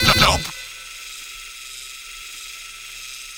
sentry_scan2.wav